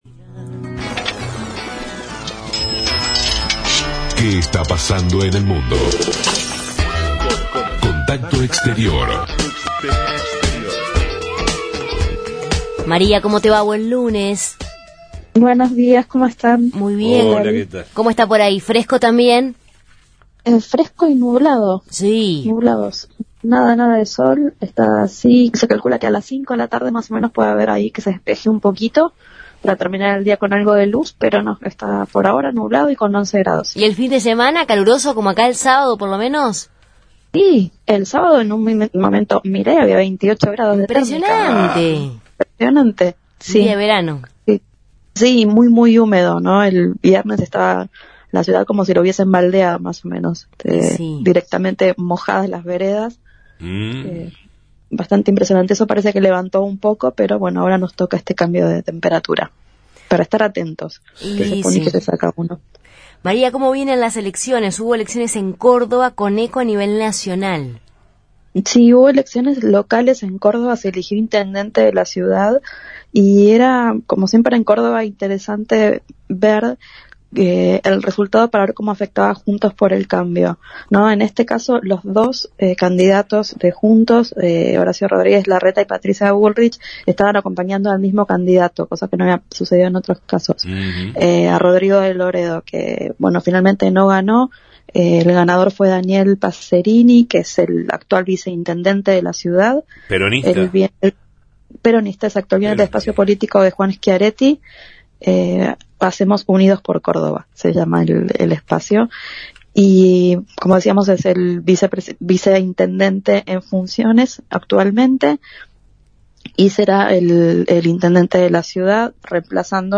Corresponsal